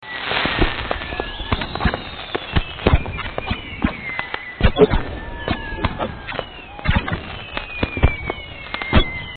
fireworksMulti.mp3